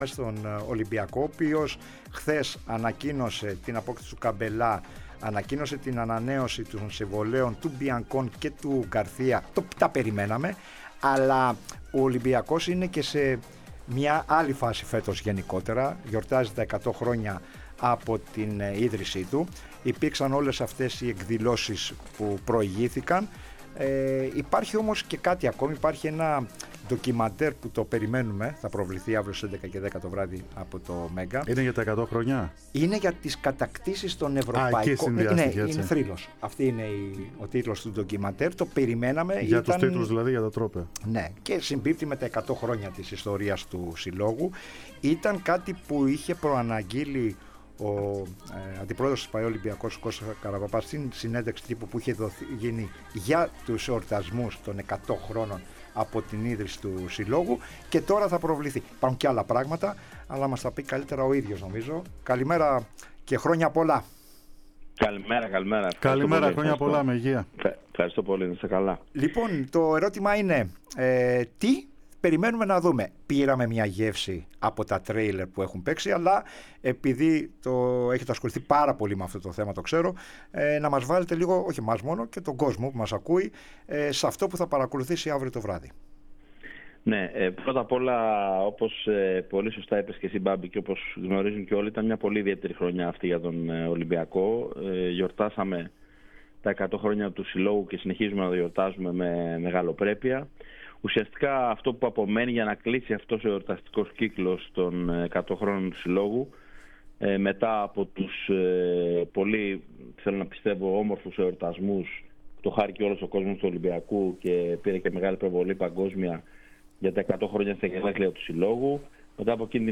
μίλησε στην εκπομπή "3-5-2" της ΕΡΑ ΣΠΟΡ